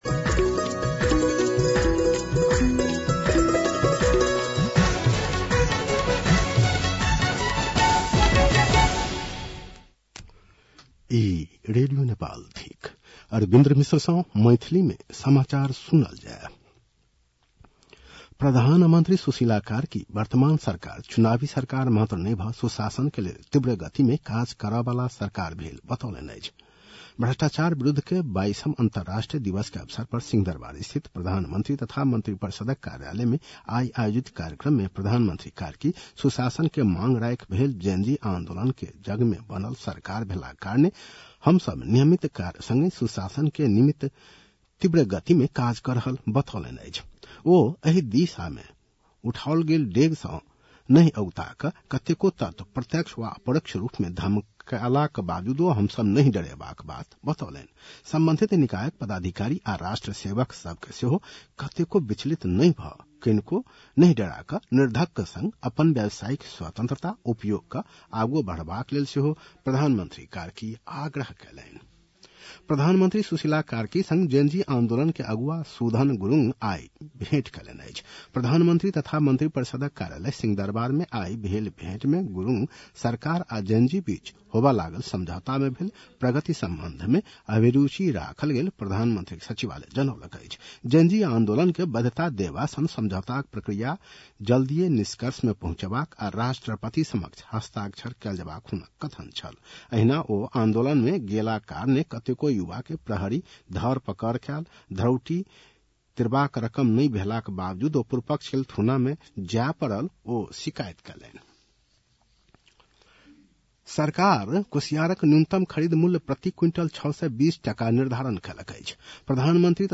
मैथिली भाषामा समाचार : २३ मंसिर , २०८२